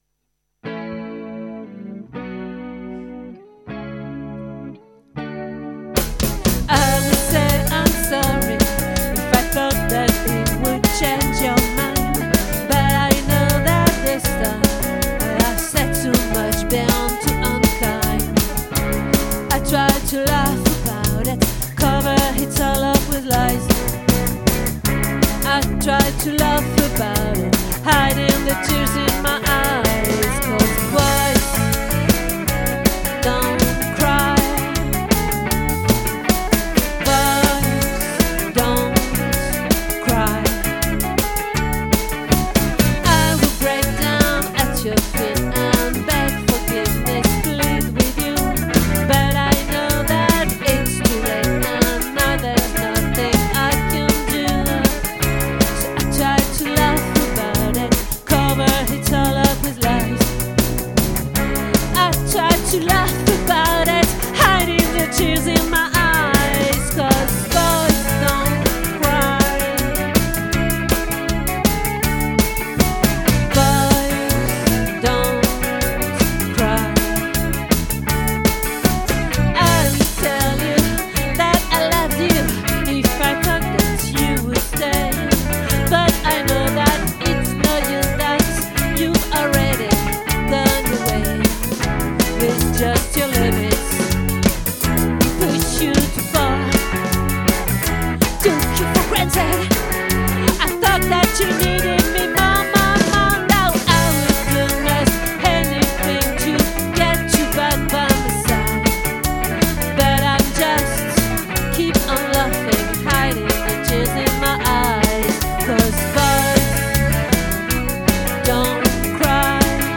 🏠 Accueil Repetitions Records_2024_10_14